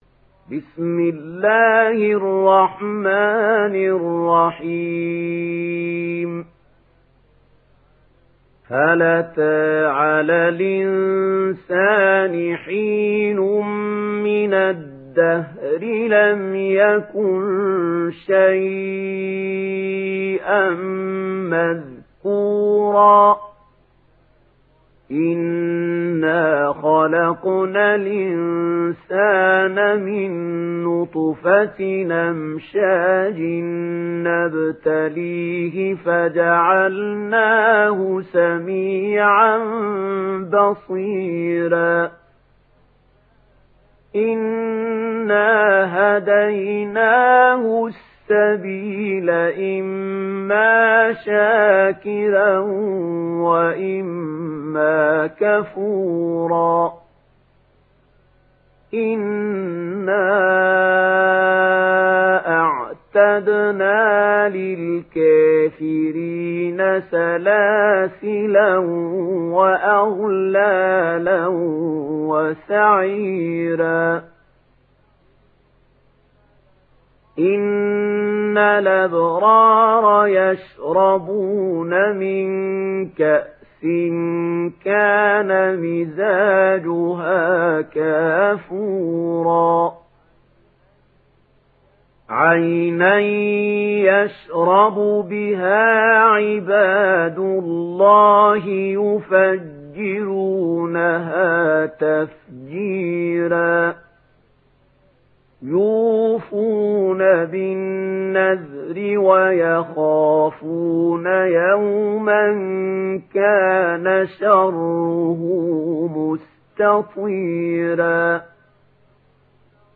Sourate Al Insan Télécharger mp3 Mahmoud Khalil Al Hussary Riwayat Warch an Nafi, Téléchargez le Coran et écoutez les liens directs complets mp3